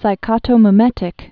(sī-kŏtō-mə-mĕtĭk, -mī-)